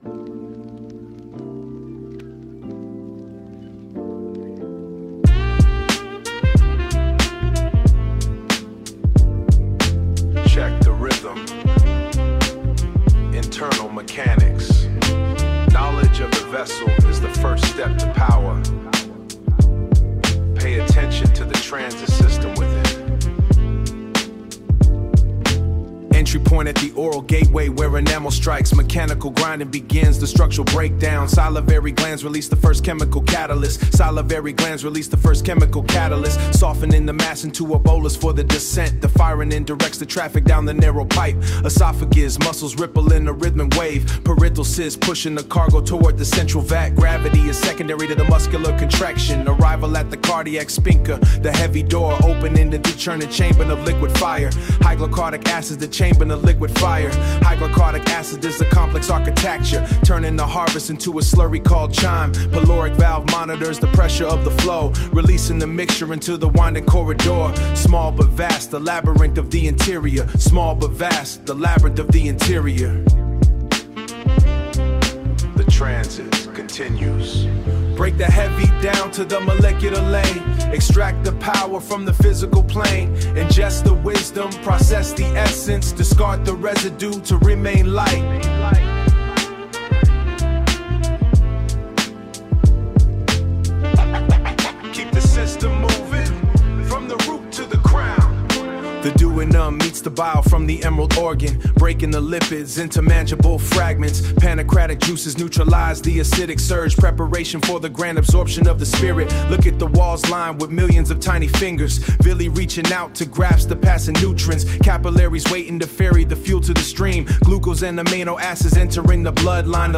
Conscious Hip-Hop, Boom-Bap, Jazz-Rap, dusty MPC drum breaks, walking upright bass, warm Rhodes piano chords, soulful saxophone loops, mid-tempo groove, male narrator, gritty yet clear vocal tone, intellectual authoritative delivery, 92 BPM, key of D minor, earthy textures, rhythmic education, organic street philosopher vibe.